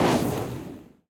missileSmall.ogg